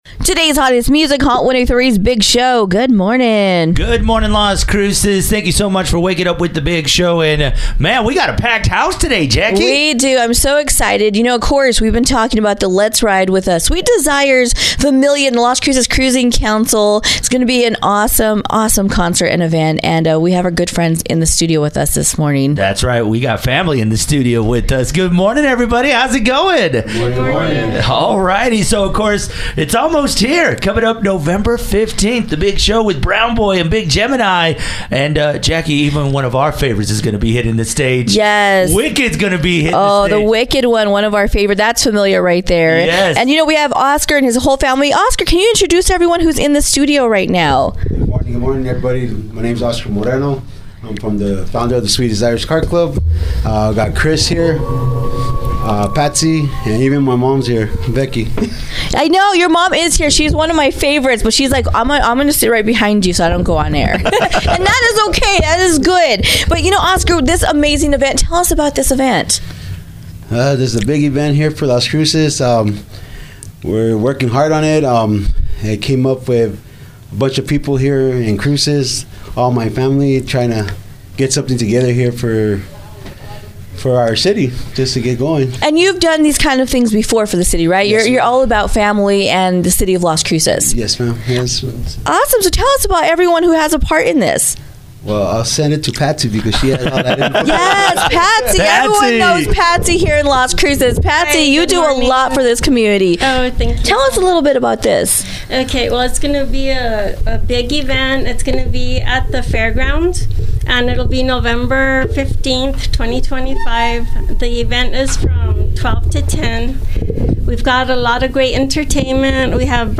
sweetdesiresinterview.mp3